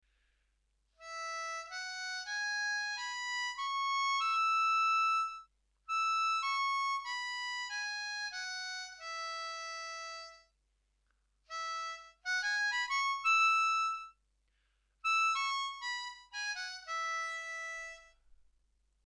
For now though, we’re using a regular Richter tuned 10 hole diatonic harmonica.
We’re in cross harp (second position) on an A harmonica – key of E major.
Upper Octave Country Scale
Country-Scale-Upper-Octave.mp3